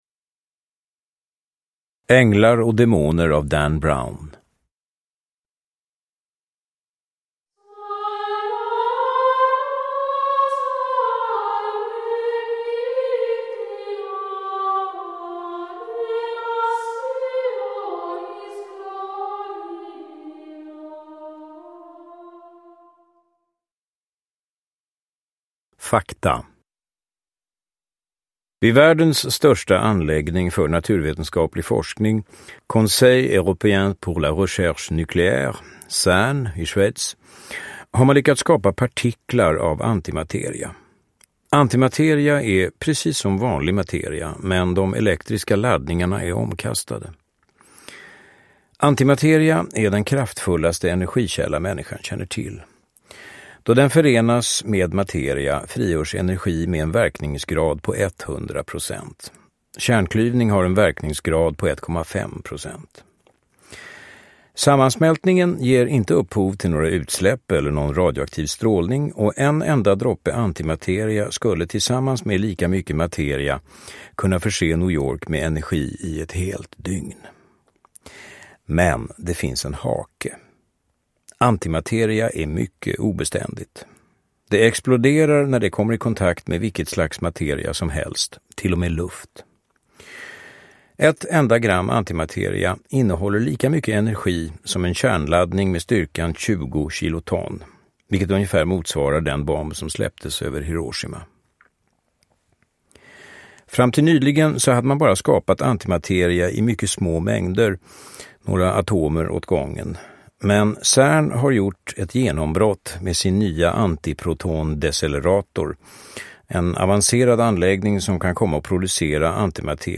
Änglar och demoner – Ljudbok – Laddas ner
Uppläsare: Johan Rabaeus